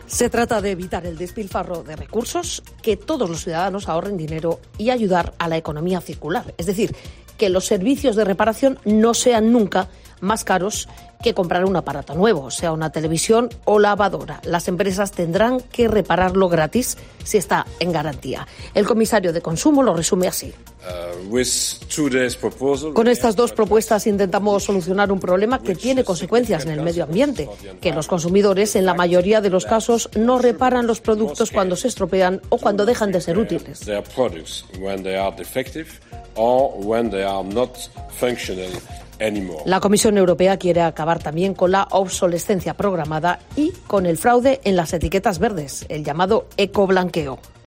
Te cuenta más detalles Paloma García Ovejero, corresponsal de COPE en Bruselas